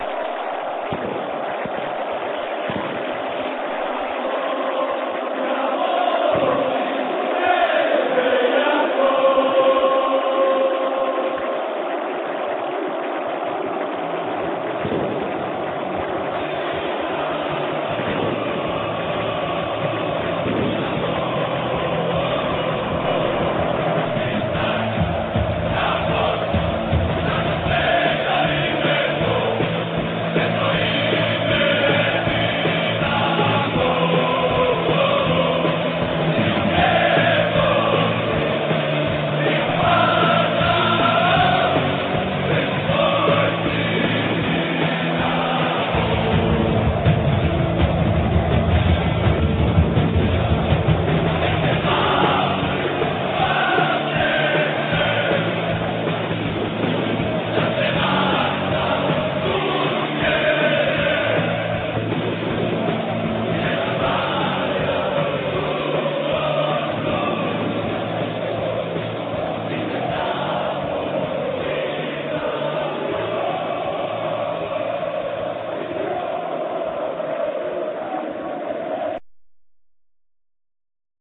Snimke od kojih je nastao film napravljene su digitalnim fotoaparatom, za vrijeme utakmica. Kvaliteta tih snimaka je loša zbog specifičnih uvjeta, te je to dodatno umanjilo kvalitetu samog filma.